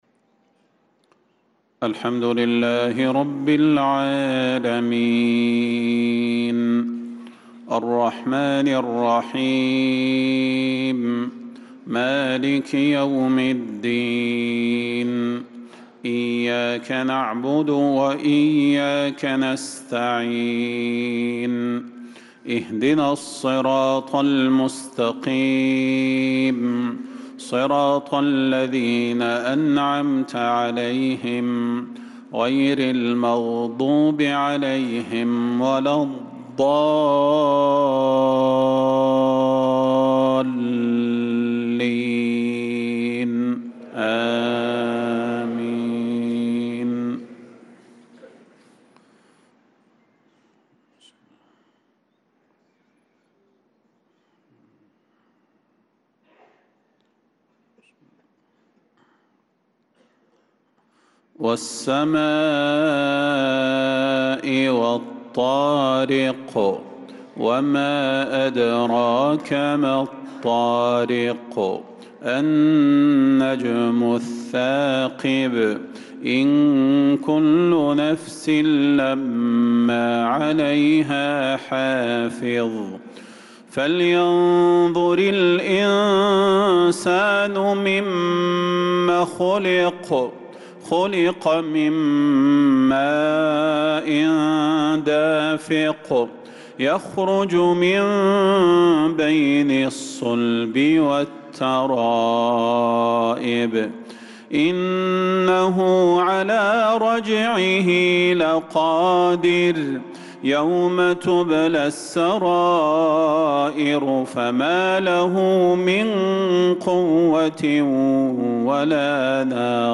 صلاة المغرب للقارئ صلاح البدير 16 شوال 1445 هـ
تِلَاوَات الْحَرَمَيْن .